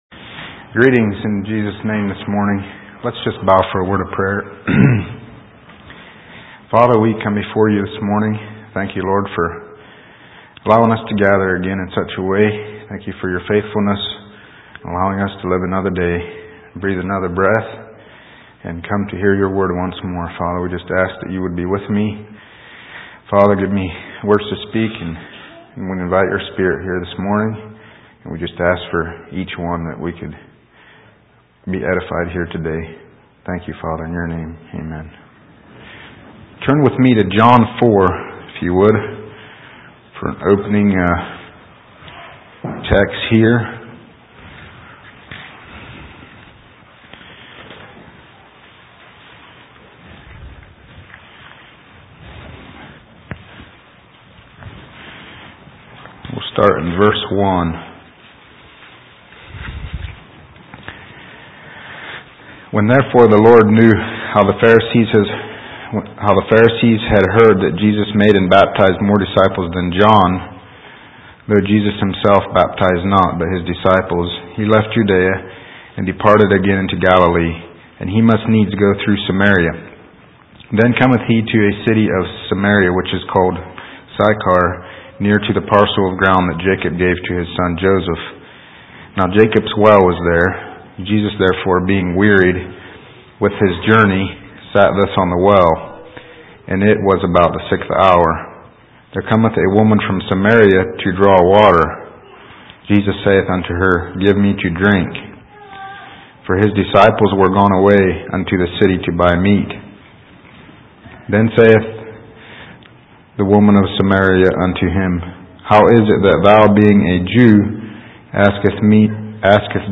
2024 Sermons 8/14